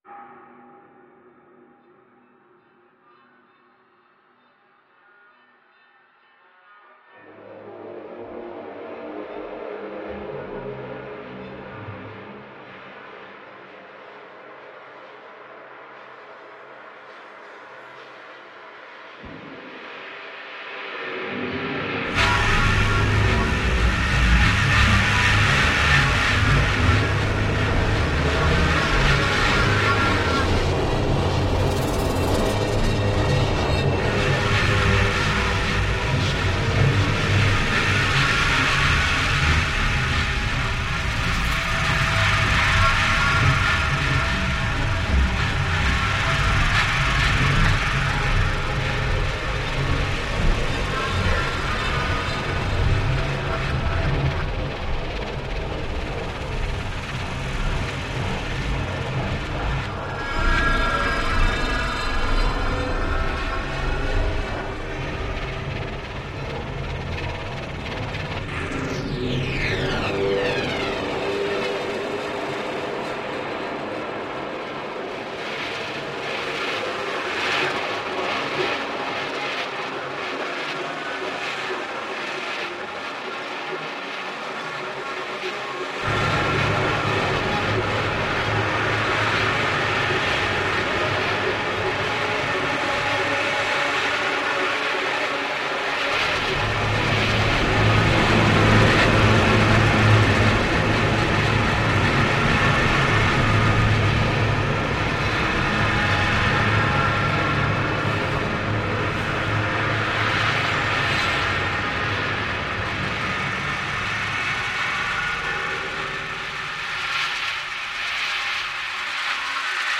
Mandalay Marionette Theatre